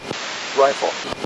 RifleD.ogg